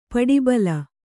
♪ paḍibala